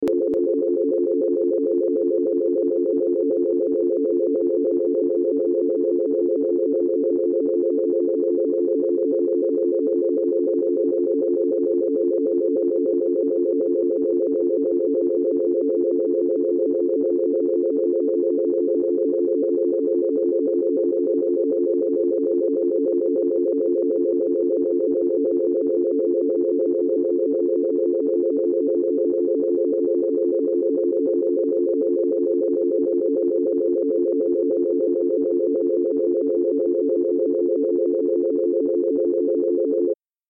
دانلود صدای تلفن 5 از ساعد نیوز با لینک مستقیم و کیفیت بالا
جلوه های صوتی